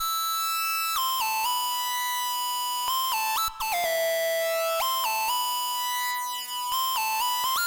Jazzy Drums 125
描述：爵士鼓
Tag: 125 bpm Chill Out Loops Drum Loops 1.29 MB wav Key : Unknown